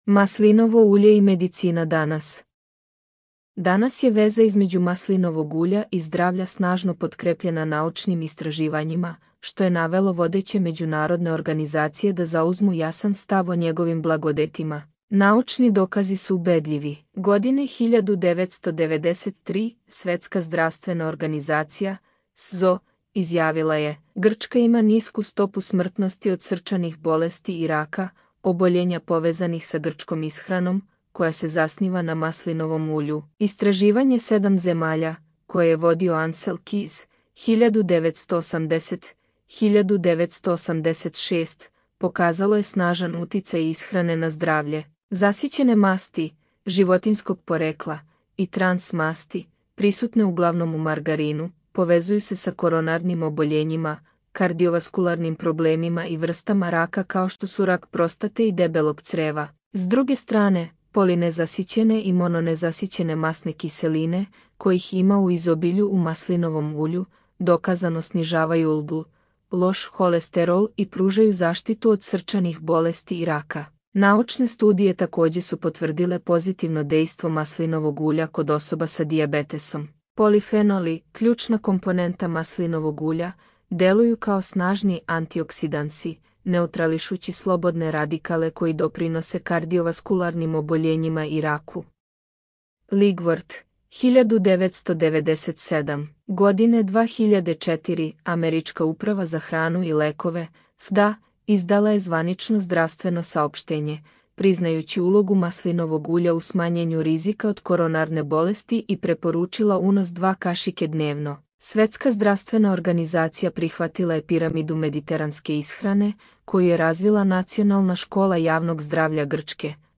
Аудио водич / Audio vodič.